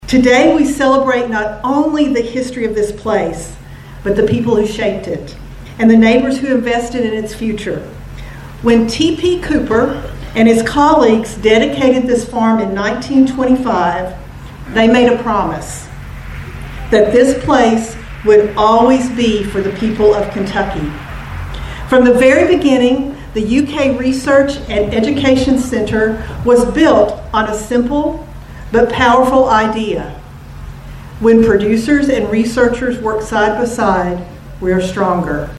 The University of Kentucky leadership and board of trustees were joined by farmers, state and local officials, and community members to celebrate a century of service at the Research and Education Center in Princeton.